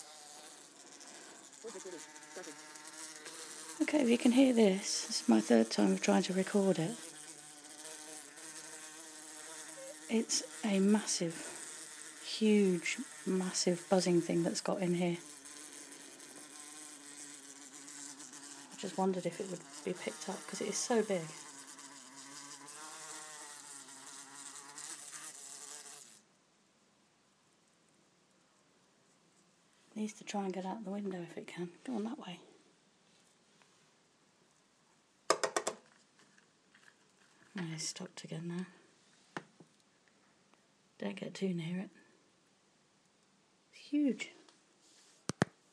Buzzing thing!